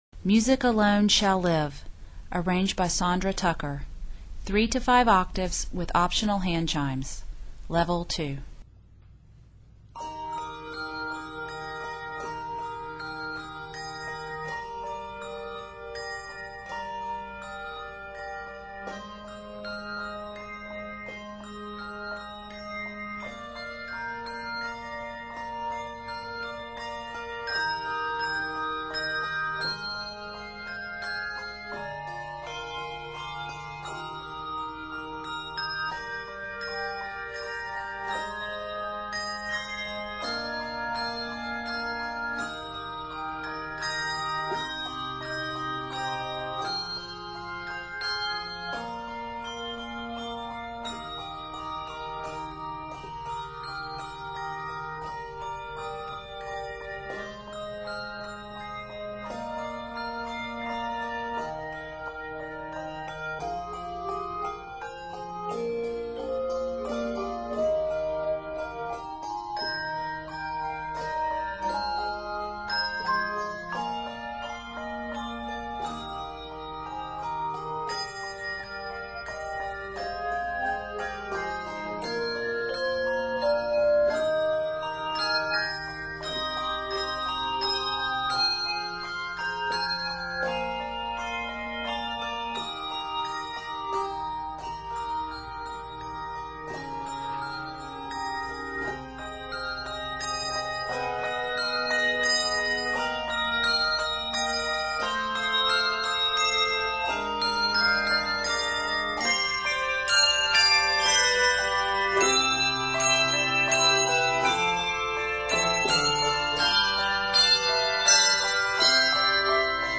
flowing arrangement
Scored in Eb and C Major, this composition is 72 measures.